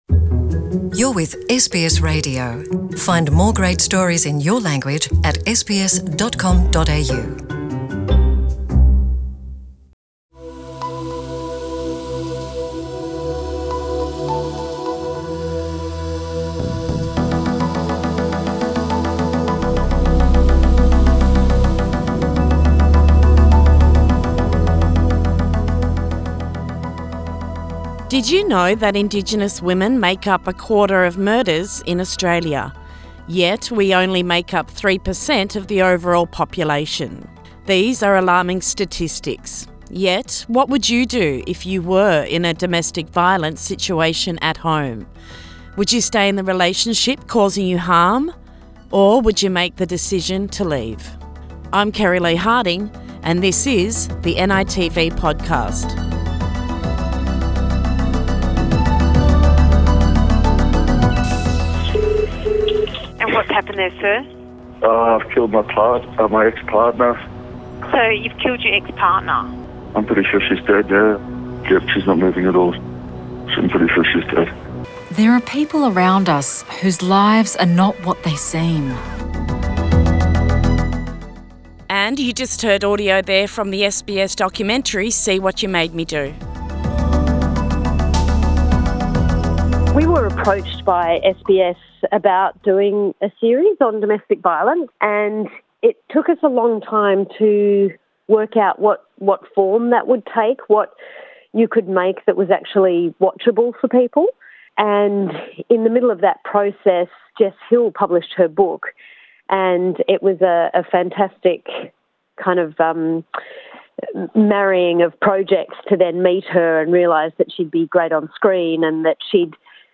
In this episode of the NITV Podcast series we explore the new three-part documentary series See What You Made Me Do which is on SBS, NITV and SBS on Demand. Interviews